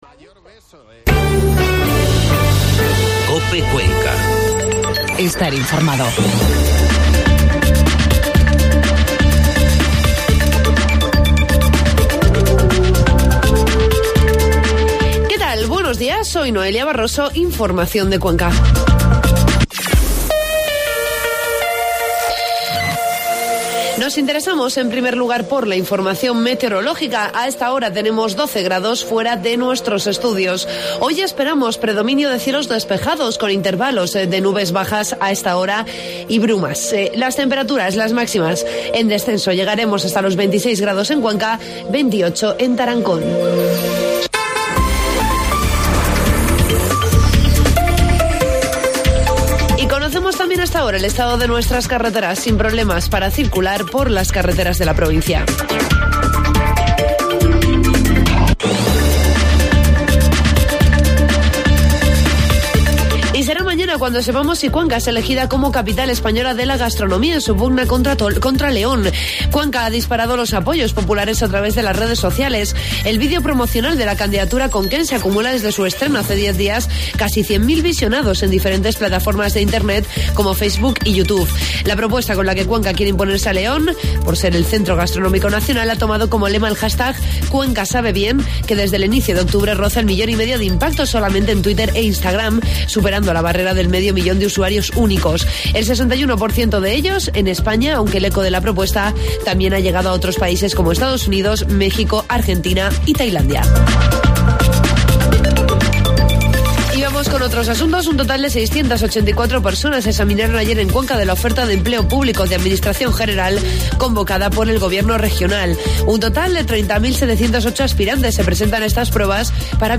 Informativo matinal COPE Cuenca 16 de octubre